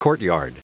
Prononciation du mot courtyard en anglais (fichier audio)
Prononciation du mot : courtyard